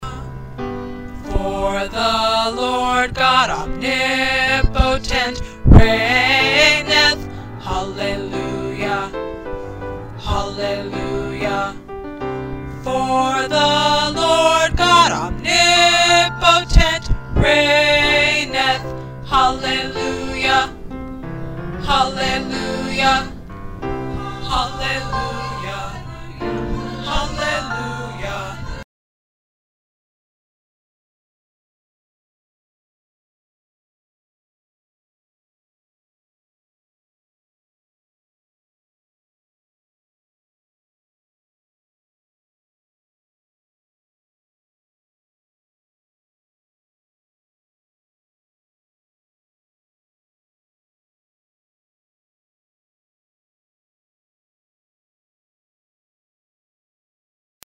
In general, the bass parts in the score are pretty high.